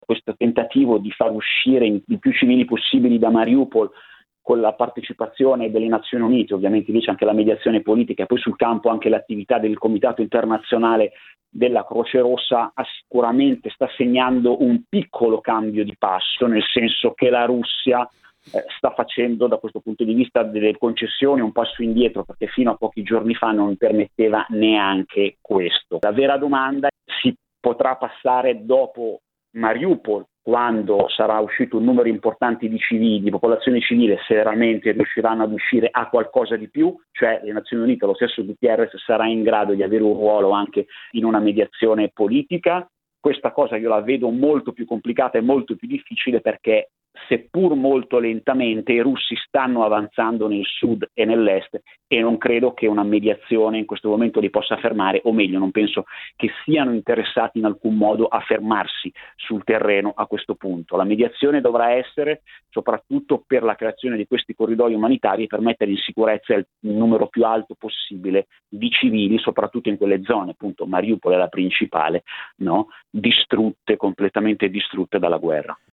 Riuscirà l’Onu a mediare tra le due parti, Kiev e Mosca, almeno sull’evacuazione dei civili? Da Kiev